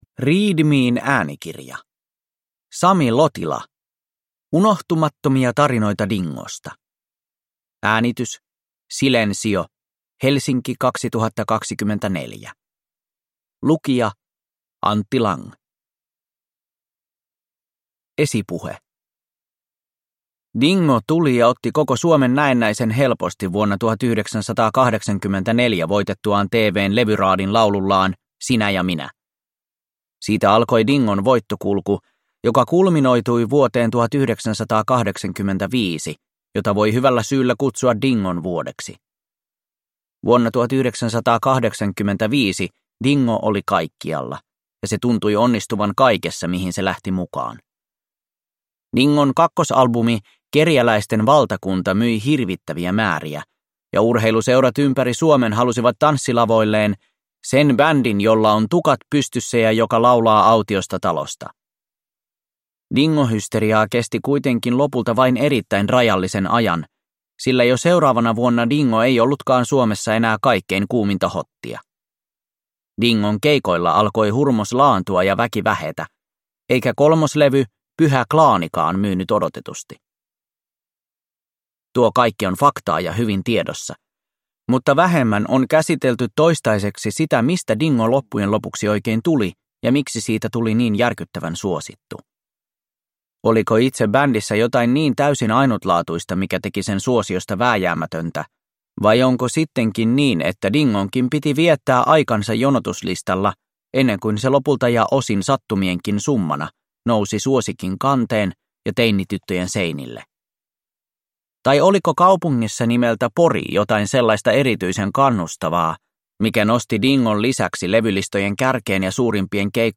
Unohtumattomia tarinoita Dingosta – Ljudbok